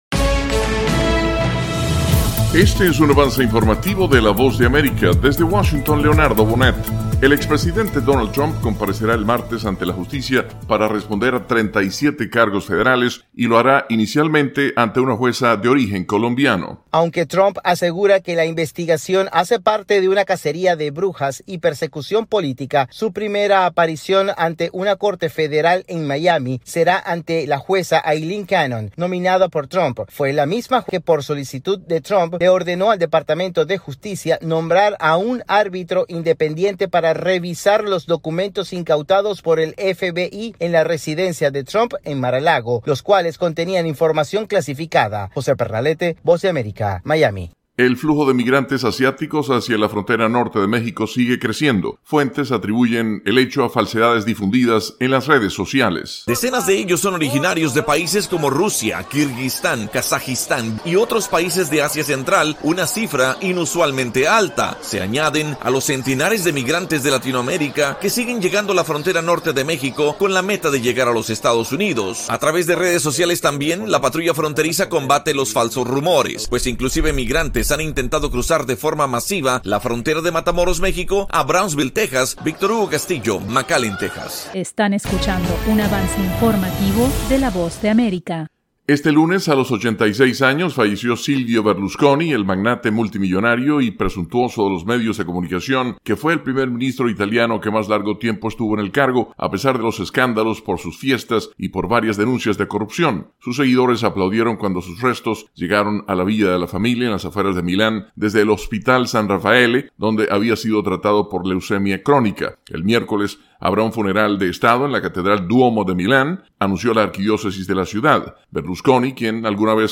Avance Informativo 3:00 PM